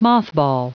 Prononciation du mot mothball en anglais (fichier audio)
Prononciation du mot : mothball